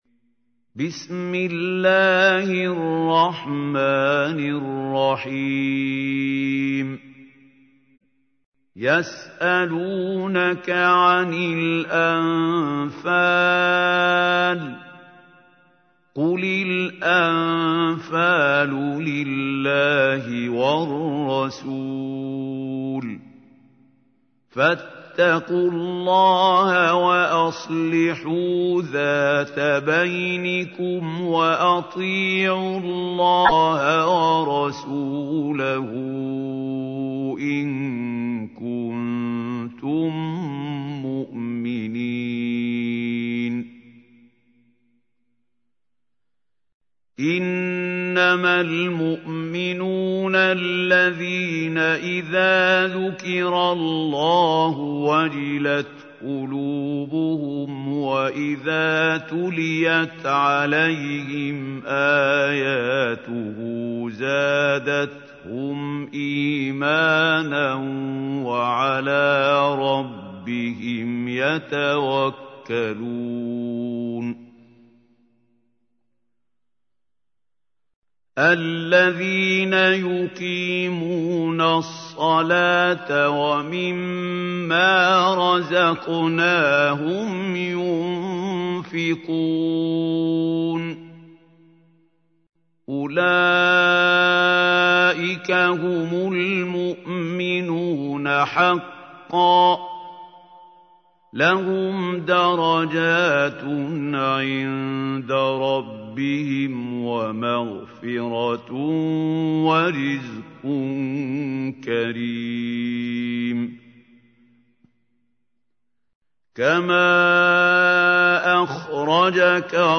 تحميل : 8. سورة الأنفال / القارئ محمود خليل الحصري / القرآن الكريم / موقع يا حسين